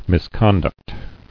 [mis·con·duct]